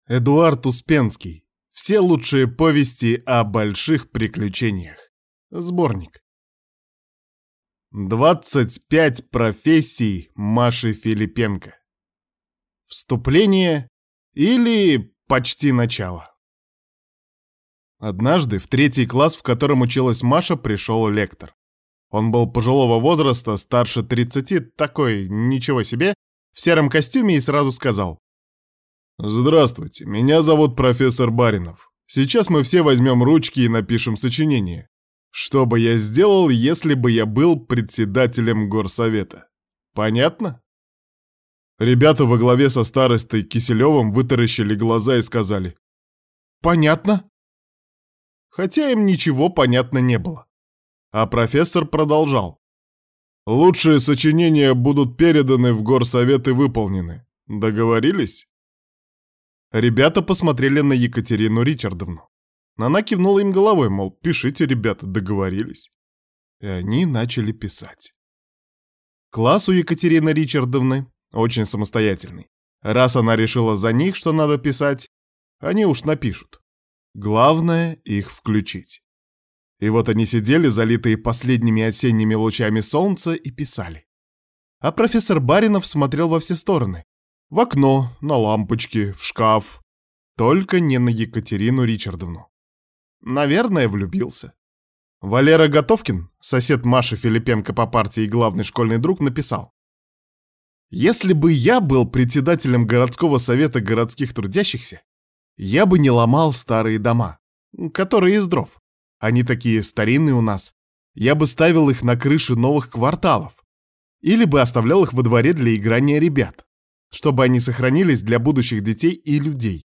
Аудиокнига Все лучшие повести о больших приключениях | Библиотека аудиокниг